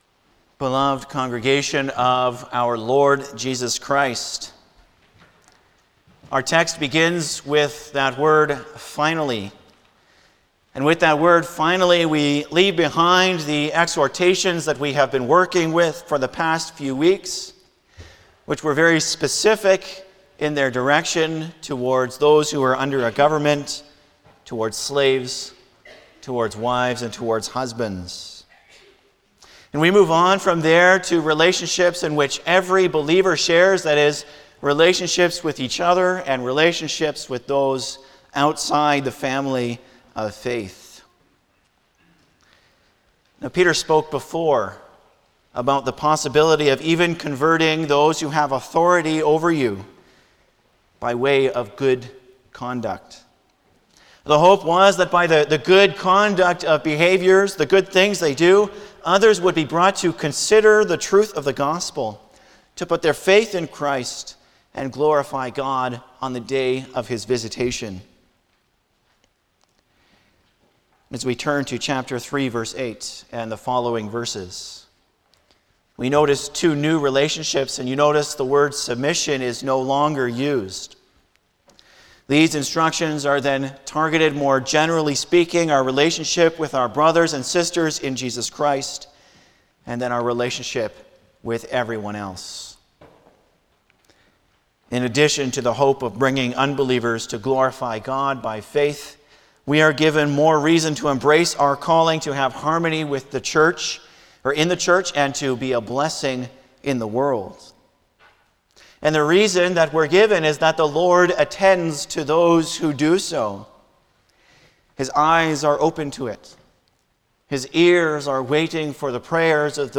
Service Type: Sunday morning
09-Sermon.mp3